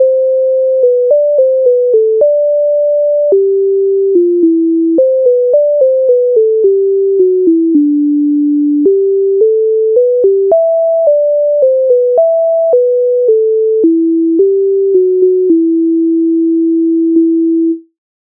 Українська народна пісня